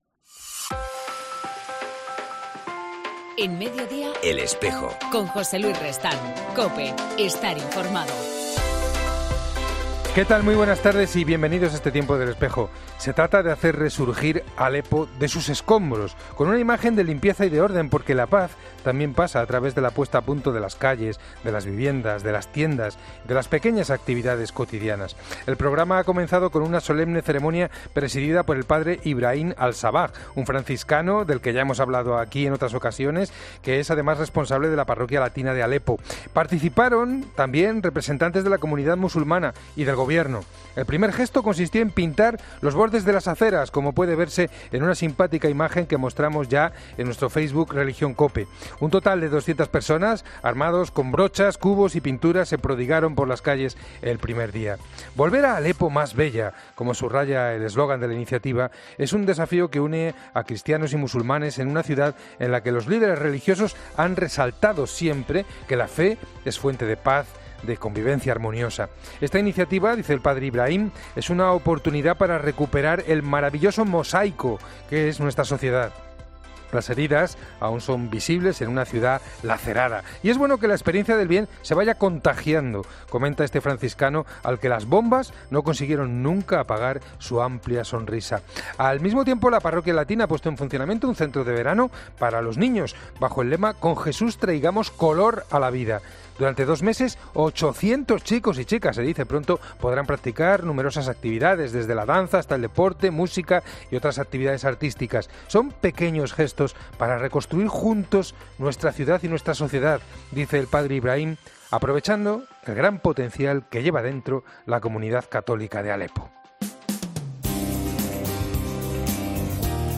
En El Espejo del 28 de junio entrevistamos a la religiosa guaneliana